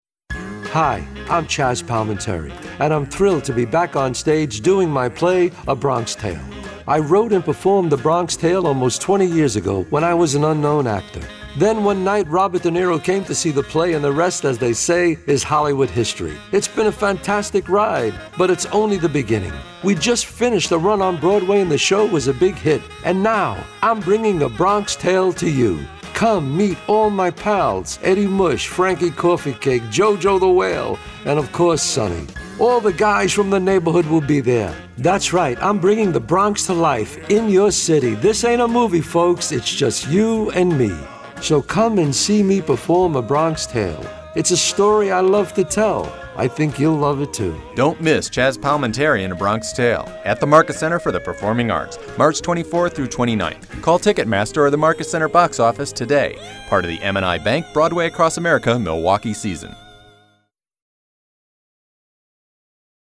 A Bronx Tale Radio Commercial